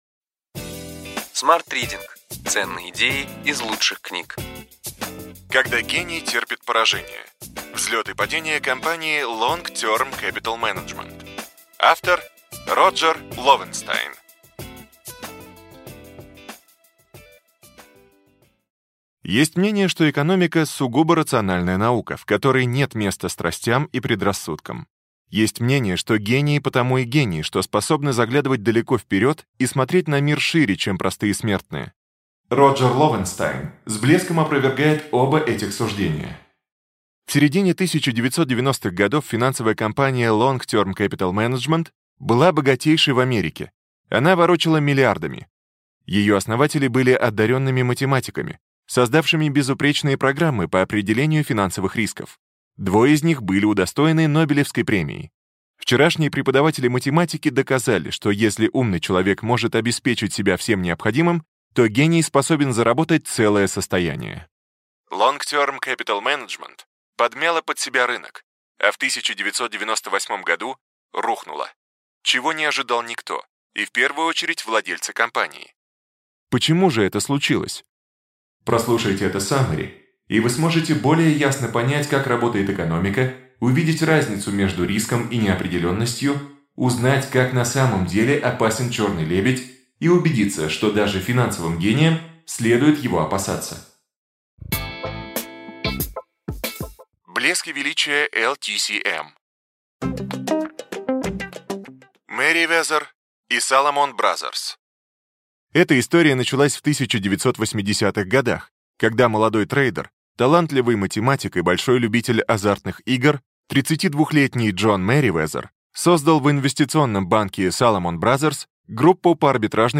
Аудиокнига Ключевые идеи книги: Когда гений терпит поражение. Взлет и падение компании Long-Term Capital Management.